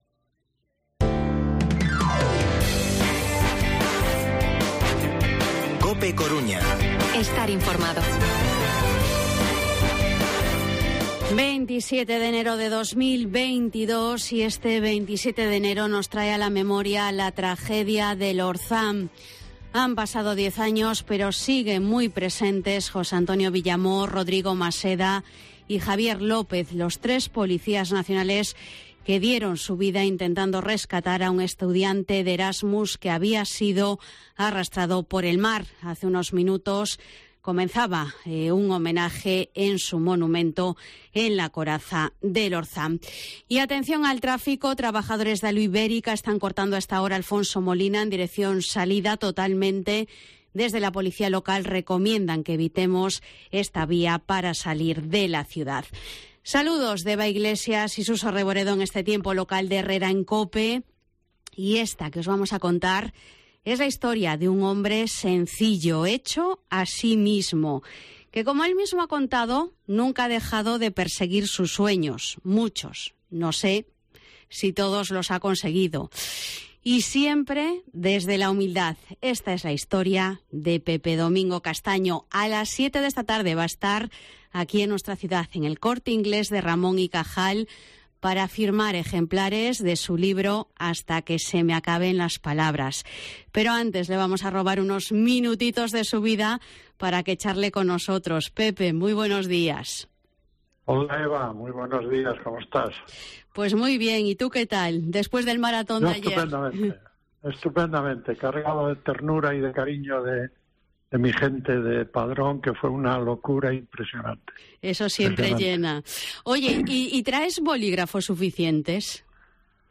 Entrevista Pepe Domingo Castaño